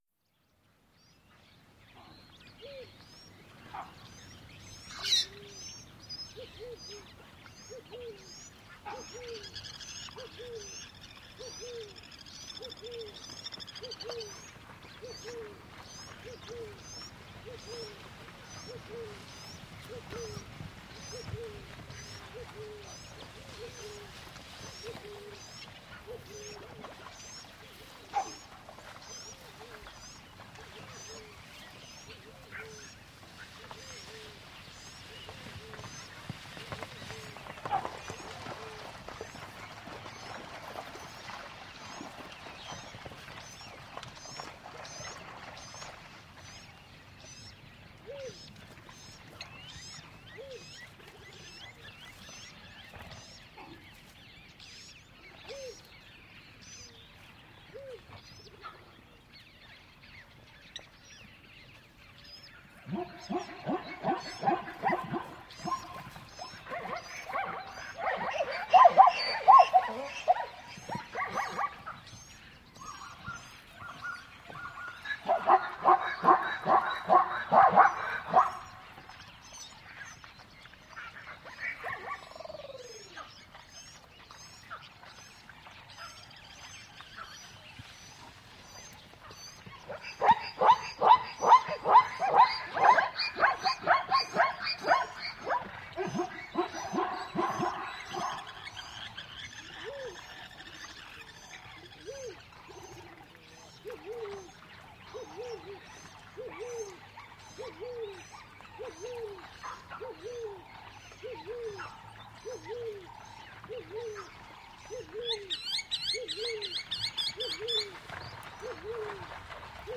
• zebras galloping and savannah dogs.wav
zebras_galloping_and_savannah_dogs_hx7.wav